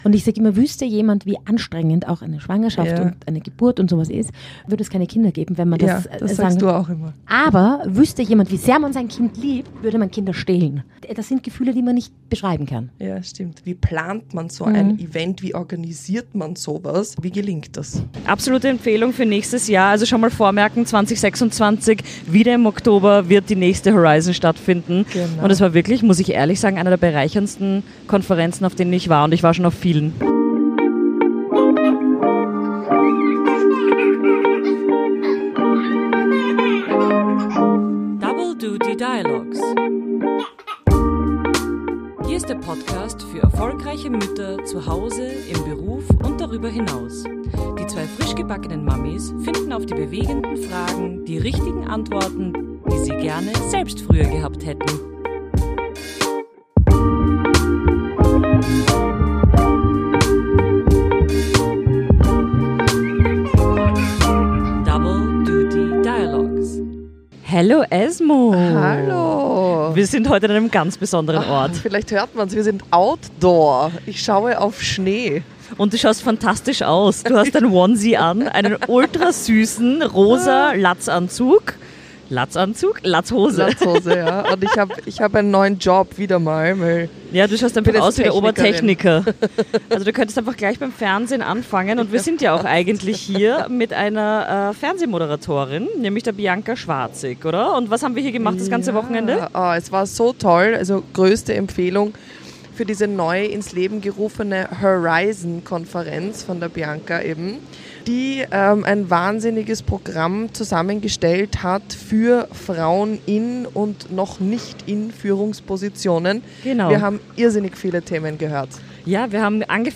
Beschreibung vor 6 Monaten Eine so berührende Podcast-Aufnahme über den Wolken - um genau zu sein in der Gondel über Bad Gastein.